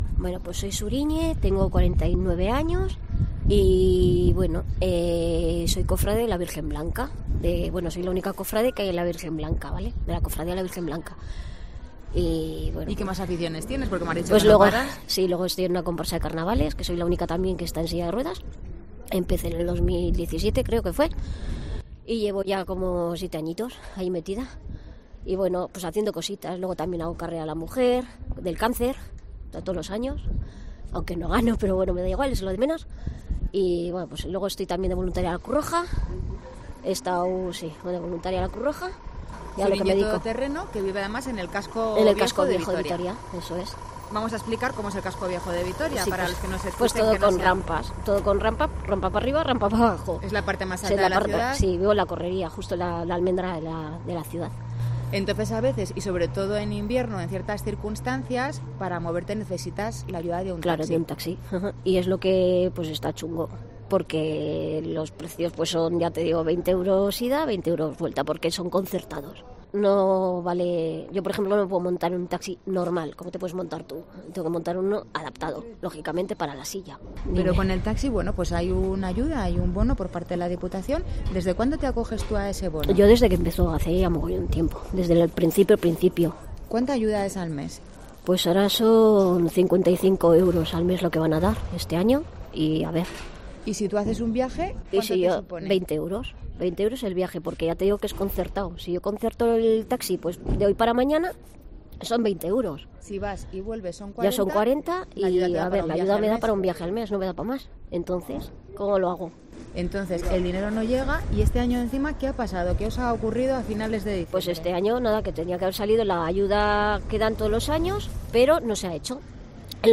COPE Euskadi charla con ella tras denunciar la falta en enero de la ayuda de 55 euros mensuales de la Diputación alavesa, a la que el PP pide comparecer por dejarles en la "cuneta"